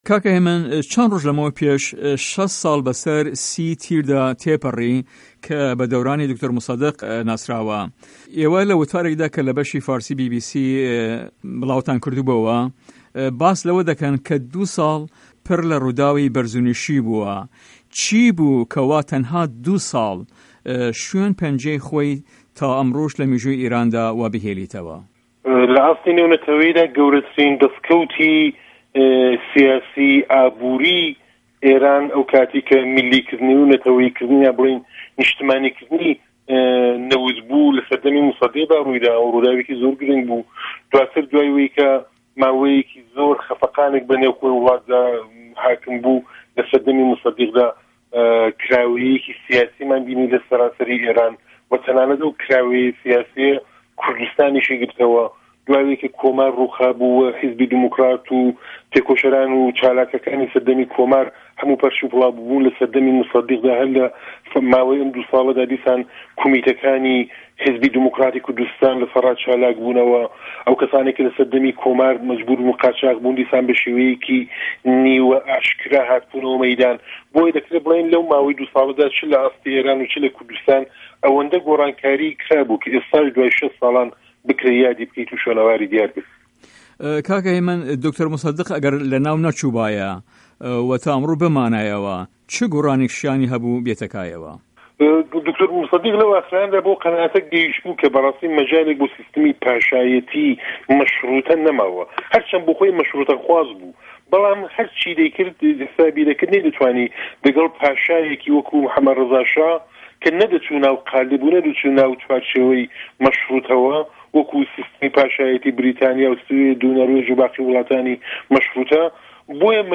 وتو وێژ